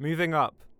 Barklines Combat VA
Added all voice lines in folders into the game folder